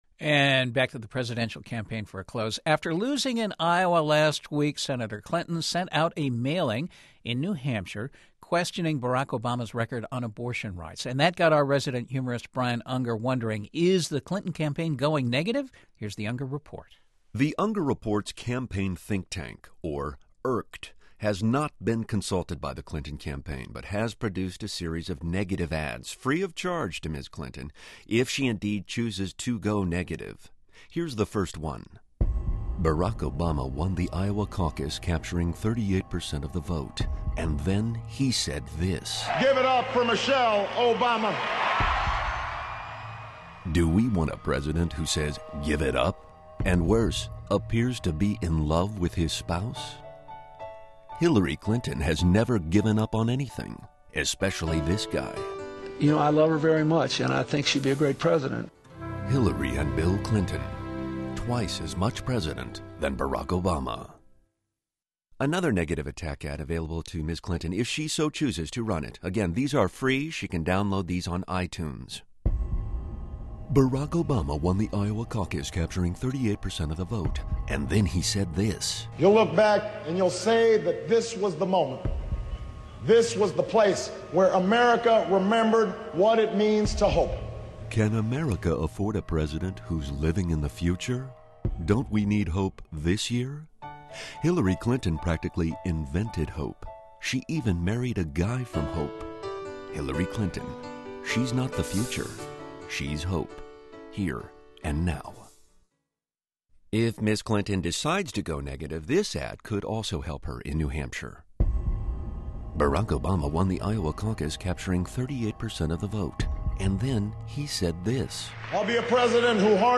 After her third-place finish in Iowa, there are reports that Hillary Clinton's campaign is thinking of running negative ads against Barack Obama. Humorist Brian Unger provides the Clinton campaign with some ready-made negative ads, free of charge.